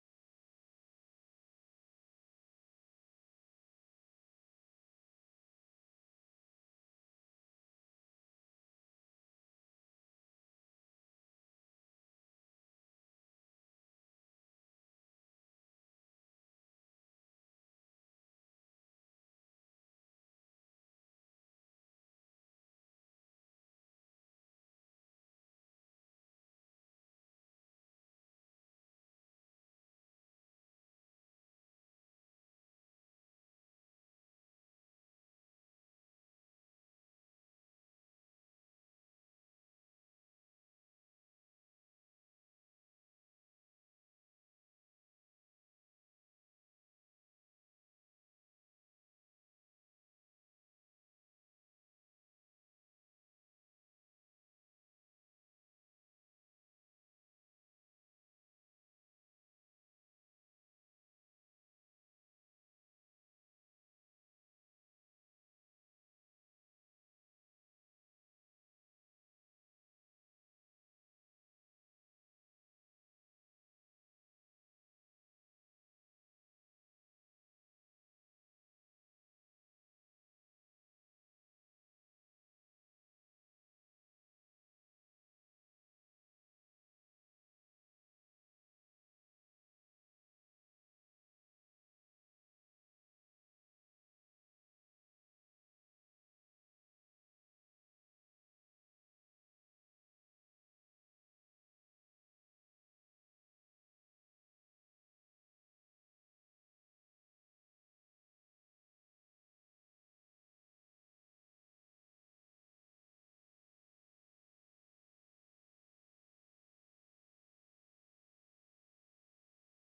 que hermosa tormenta, gracias señora del viento la lluvia y el trueno.